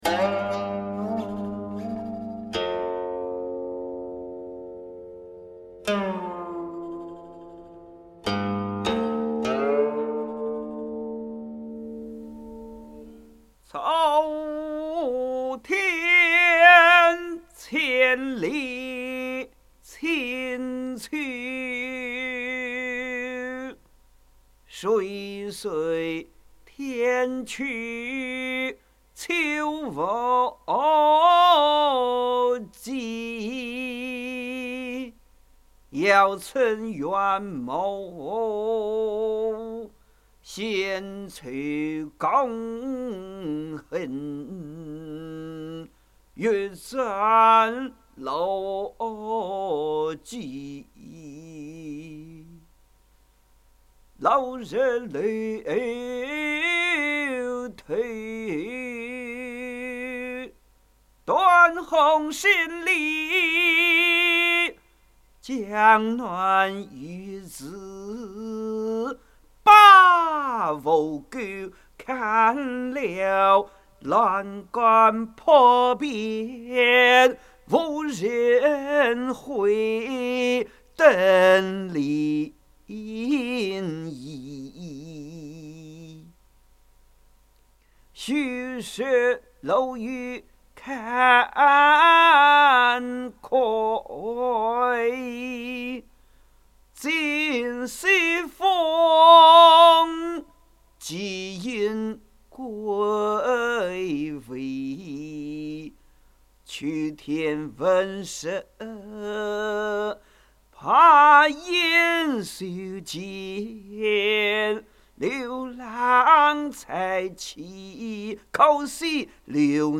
吟唱